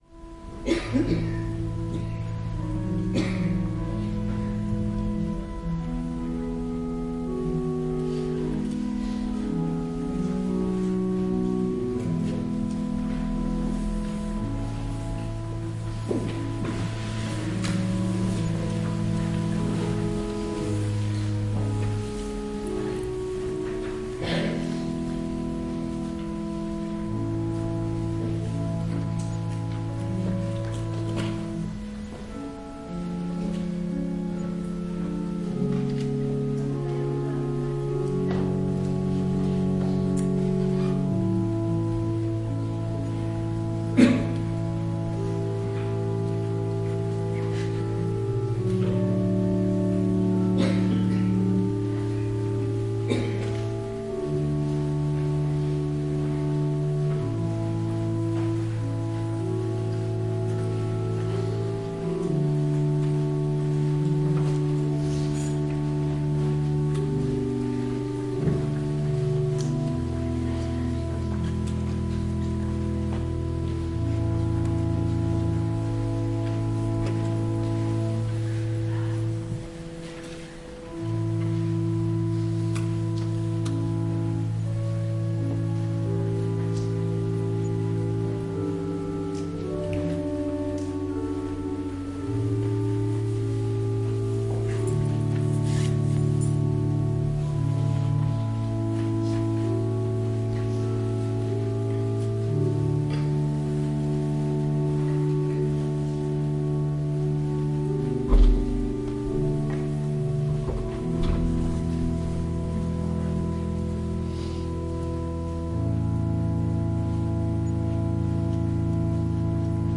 人们纷纷走出教堂
描述：人们在服务后悄悄离开教堂。器官在后台播放。
Tag: 教堂 器官 教会 宗教